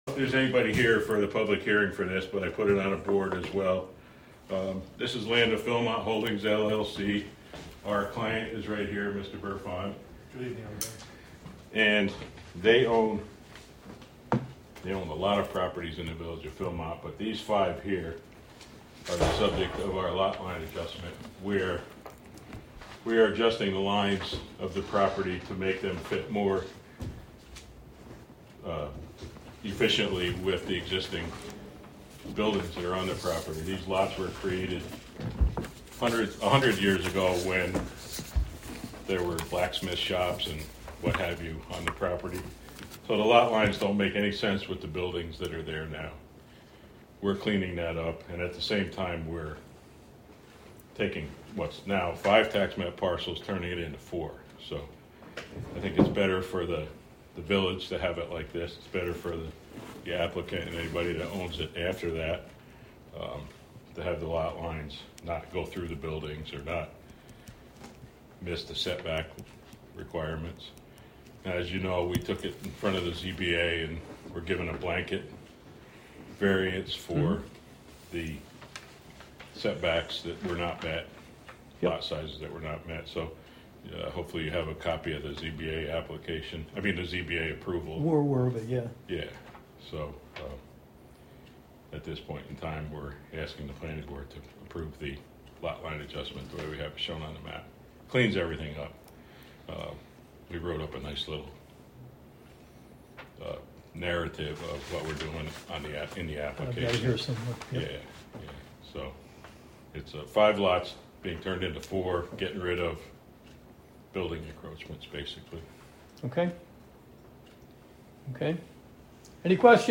Live from the Village of Philmont: Philmont Planning Board Meeting 8-3-22 (Audio)